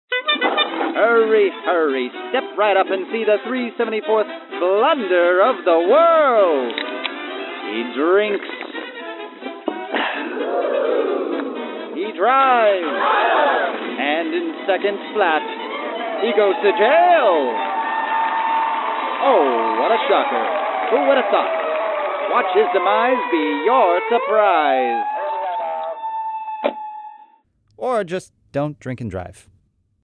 DUI public service announcement.